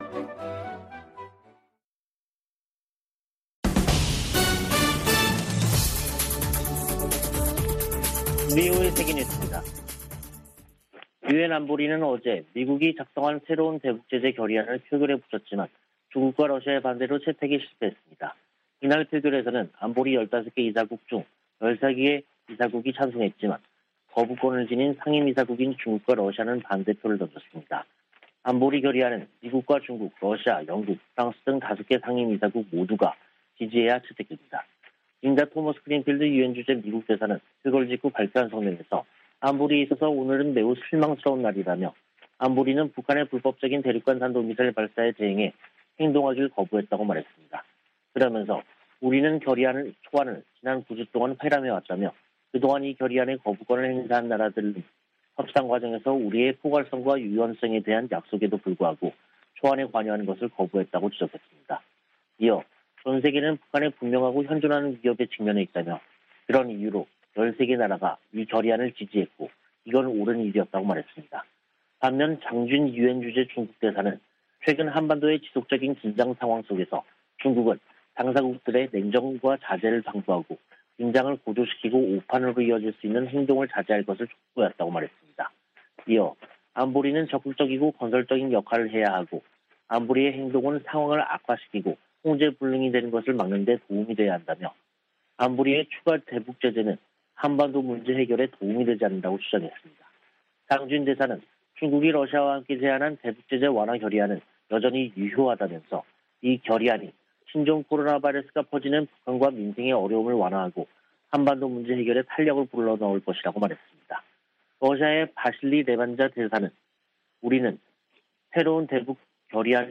VOA 한국어 간판 뉴스 프로그램 '뉴스 투데이', 2022년 5월 27일 2부 방송입니다. 유엔 안보리가 중국과 러시아의 반대로 새 대북 결의안 채택에 실패했습니다. 토니 블링컨 미 국무장관은 대중국 전략을 공개하면서 북한 핵 문제를 상호 ‘협력 분야’로 꼽았습니다. 미 국무부가 올해 초 제재한 북한 국적자 등의 이름을 연방관보에 게시했습니다.